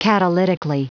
Prononciation du mot : catalytically